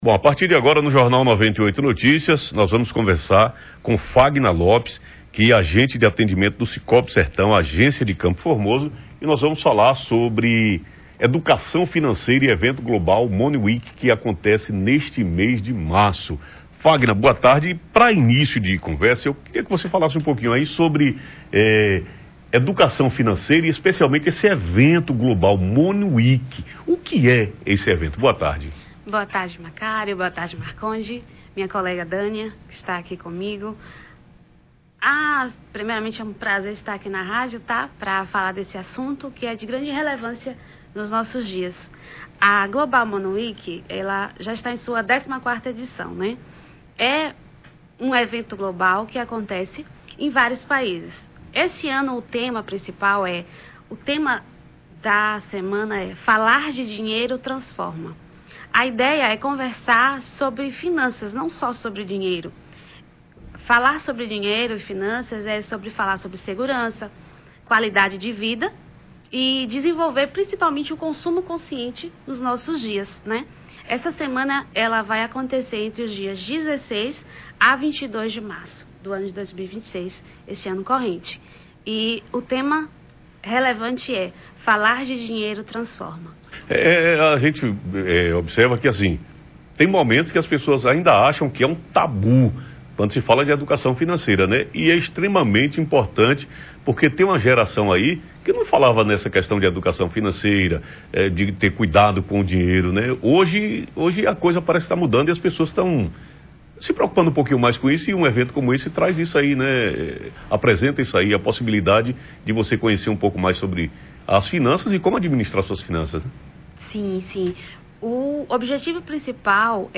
Especialistas em gestão financeira falam sobre Educação Financeira e sobre o evento Global Money Week .
entrevista-global-completa.mp3